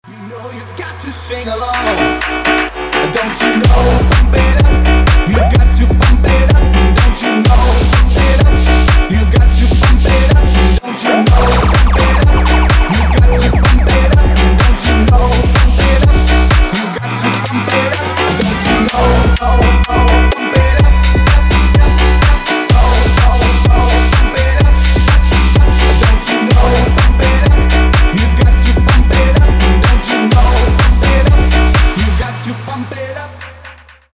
• Качество: 128, Stereo
retromix
EDM
Club House
басы
динамичные
полицейская сирена